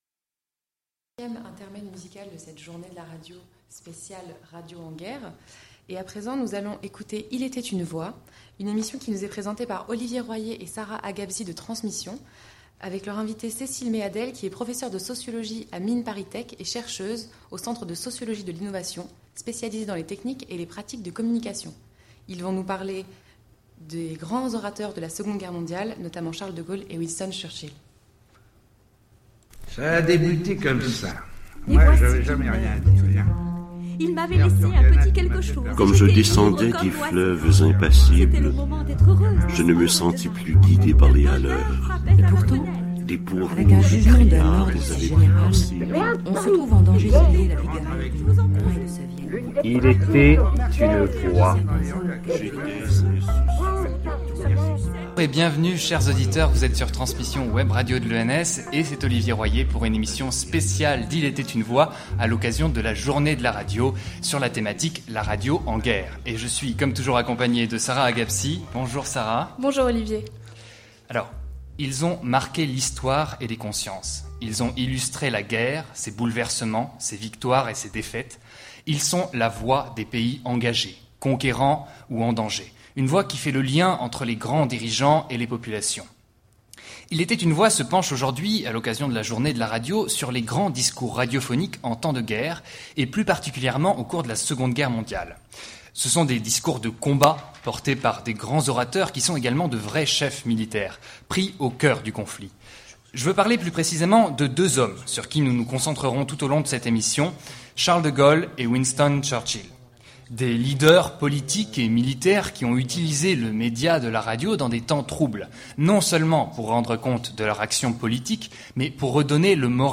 Il était une voix vous invite à interroger la voix des textes, leur mise en bouche et leur mise en son, à travers des reportages et des interviews sur la lecture à haute voix, comme art, comme discipline et comme spectacle vivant.